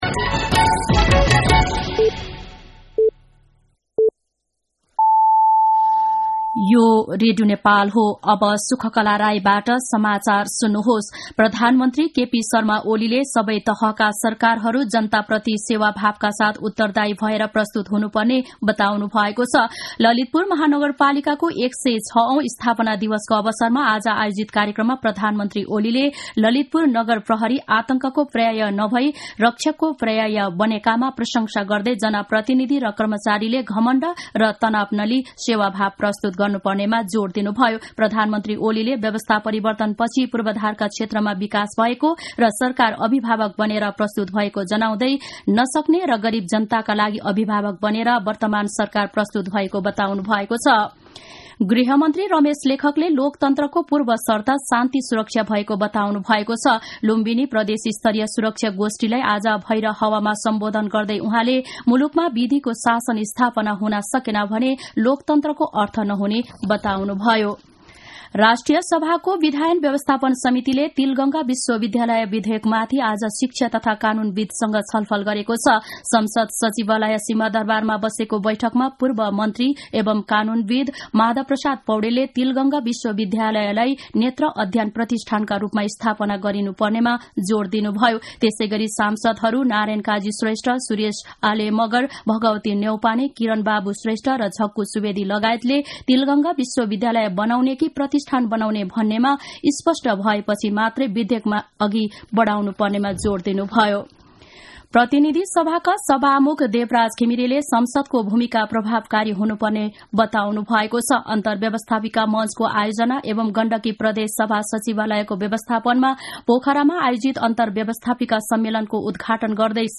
दिउँसो ४ बजेको नेपाली समाचार : ३ पुष , २०८१
4-pm-nepali-news-1-6.mp3